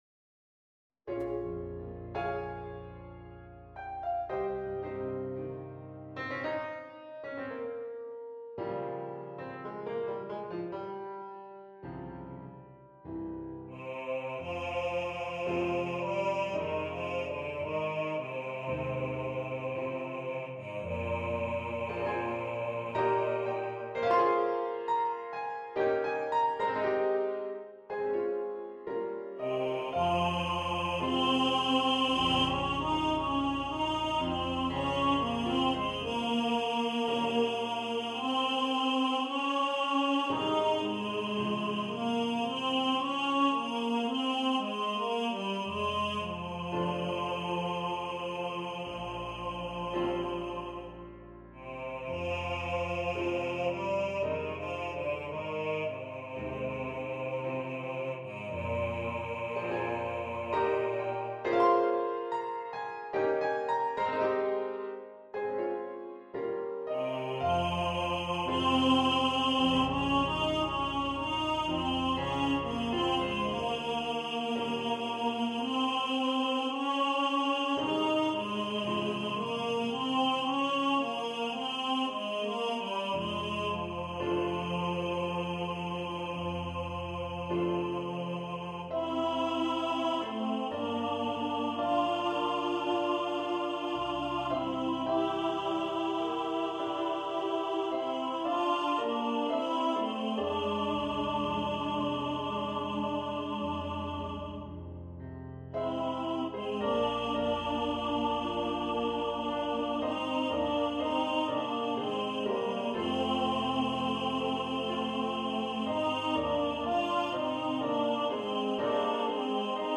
for TTBB choir
(Choir - Male voices)